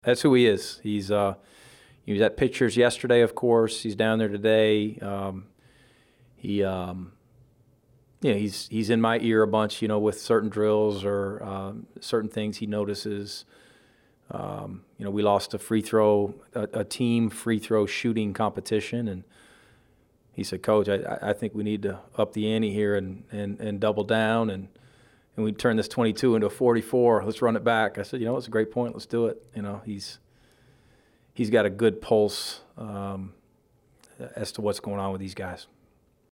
Afterward, seventh-year head coach Mike White met with the media.